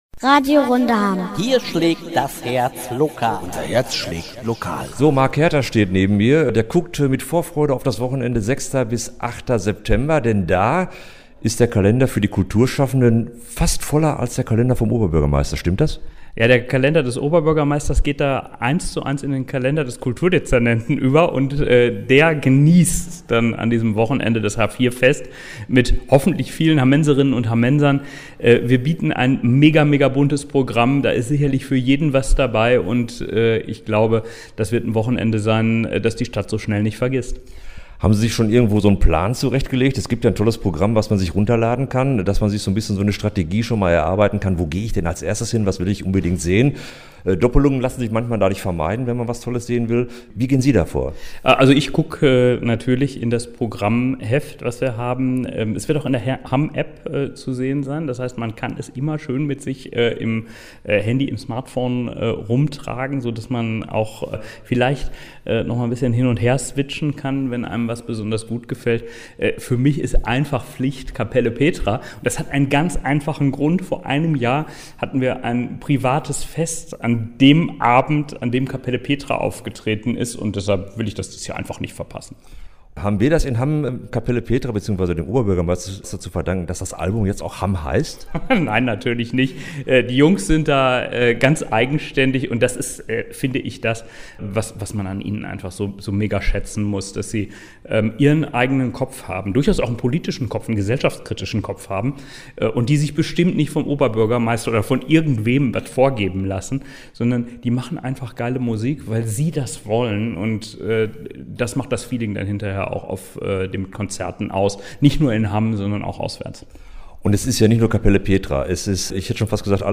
Und außerdem hat Hamms Oberbürgermeister Marc Herter im vergangenen Jahr den Auftritt der Kapelle aufgrund einer privaten Feier verpasst... er freut sich ganz besonders darauf, dass er nun eine neue Gelegenheit bekommen wird, die Jungs erneut beim Festival begrüßen zu können, wie er uns im Interview verraten hat.
Oberbürgermeister Marc Herter über das h4-Festival 2024